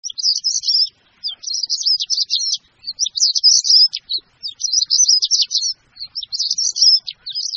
En cliquant ici vous entendrez le chant du Rouge-Gorge familier.
Le Rouge-Gorge familier